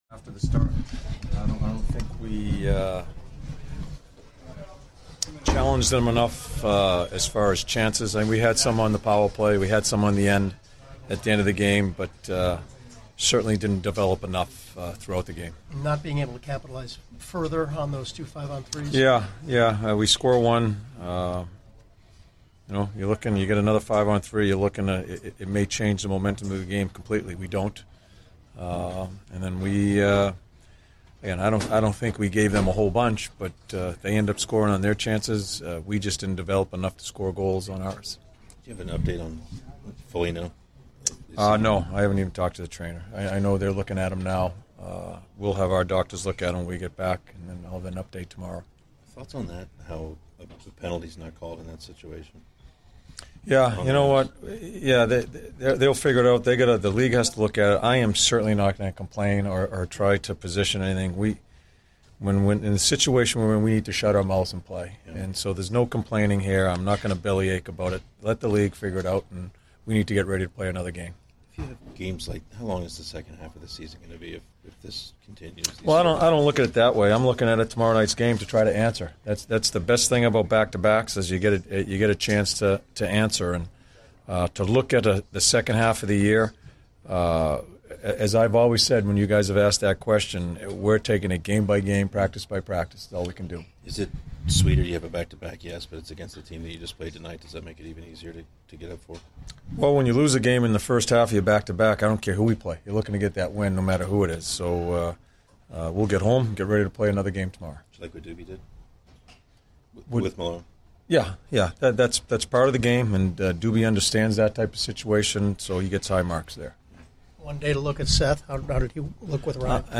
John Tortorella Post-Game 01/08/16